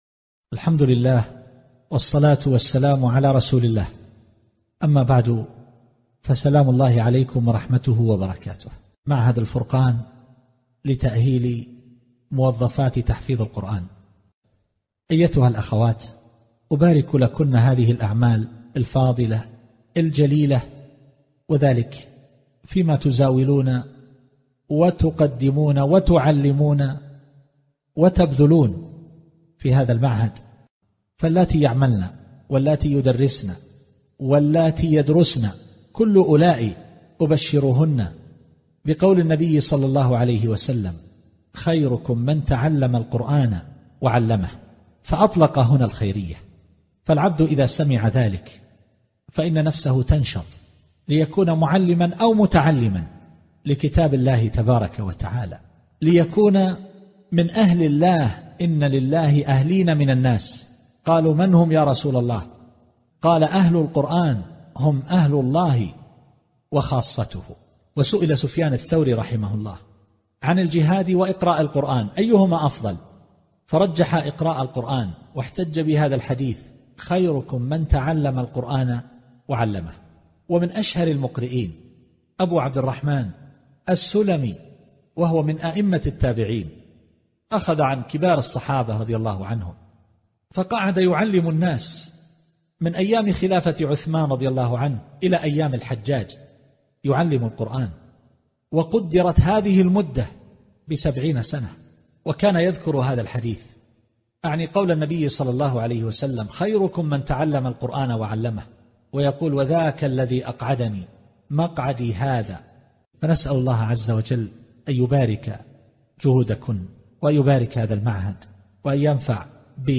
مقطع مميز كلمة الشيخ التسجيلية للأخوات الفاضلات في معهد الفرقان النسائي